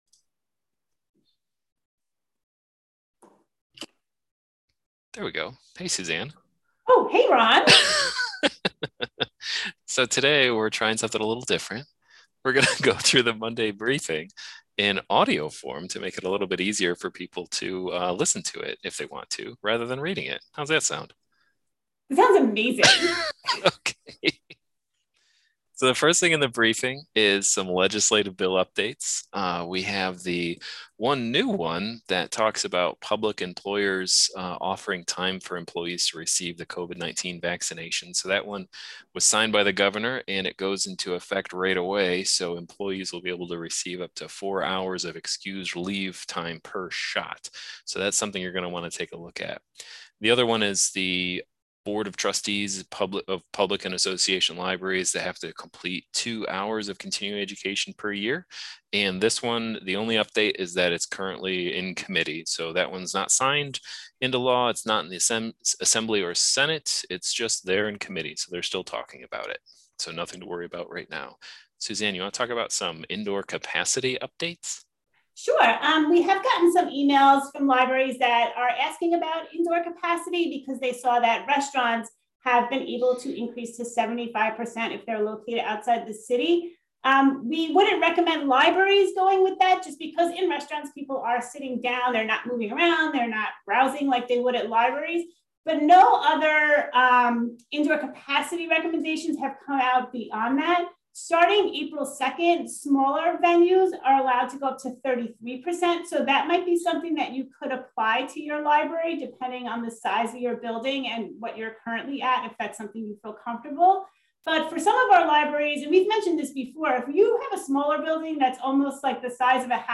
I can't guarantee we'll have time to do this every week and it's certainly low production quality, but some people might like to listen to us talk about the items below on their way into work rather than reading it.